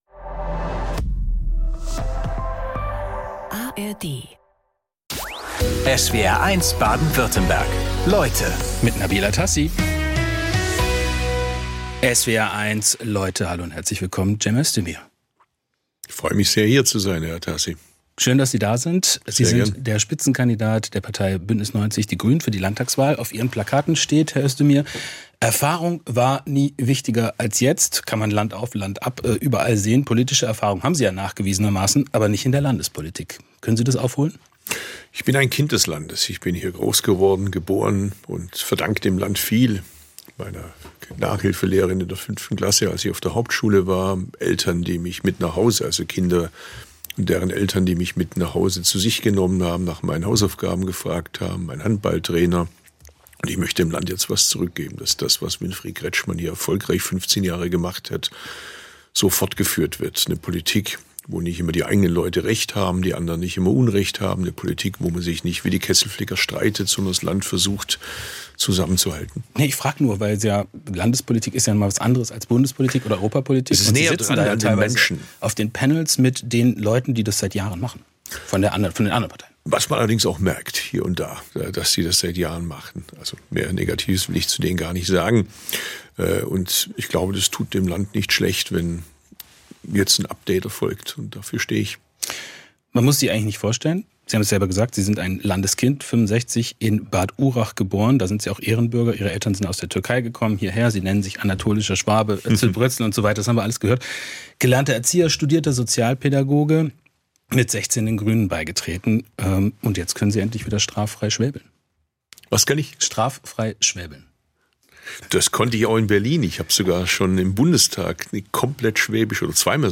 Vor der Landtagswahl 2026 sind Spitzenpolitiker:innen aus Baden-Württemberg zu Gast in SWR1 Leute, unter anderem Cem Özdemir von Bündnis 90/Die Grünen.